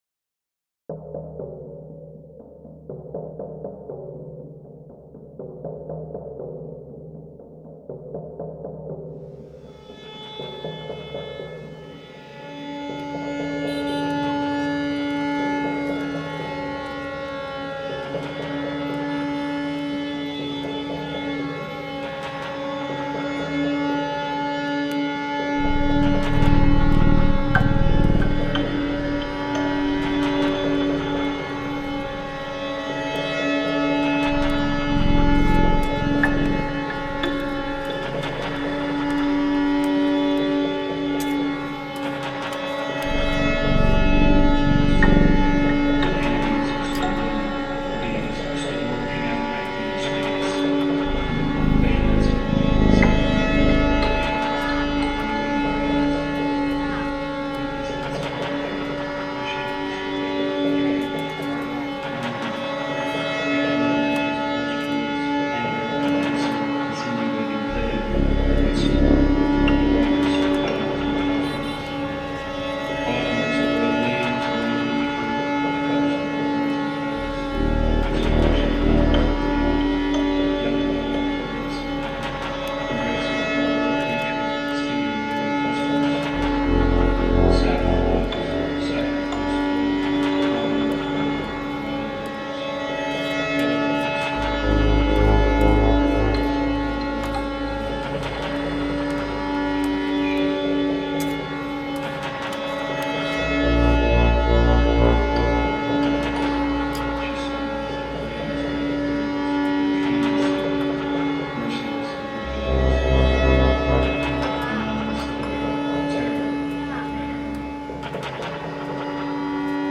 Da Lat night market reimagined